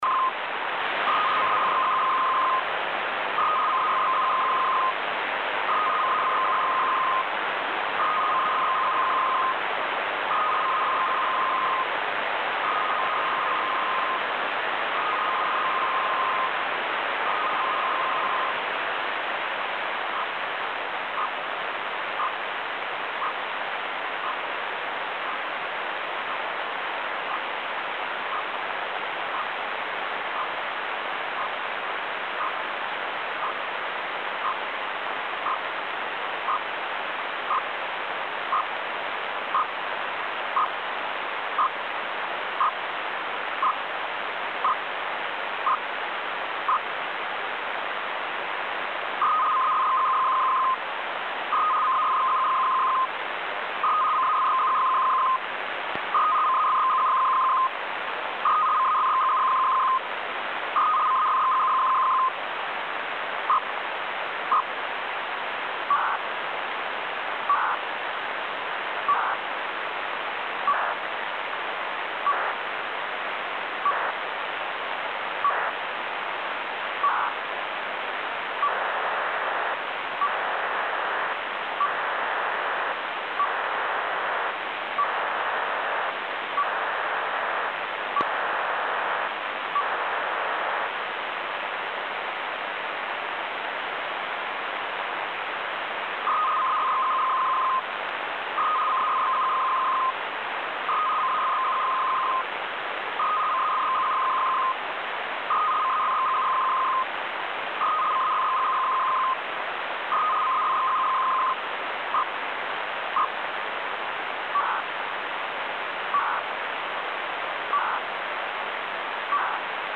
Начало » Записи » Радиоcигналы классифицированные
GW DATAPLEX на 8601 кГц